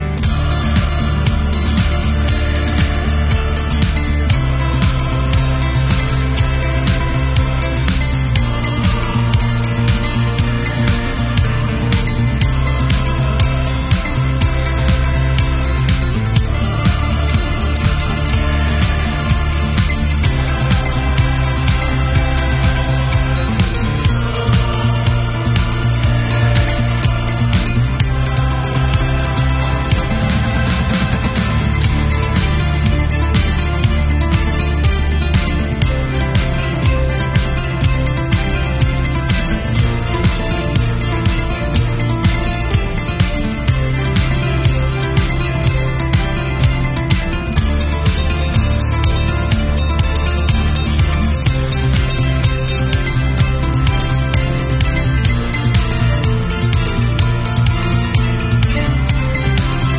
orchestral version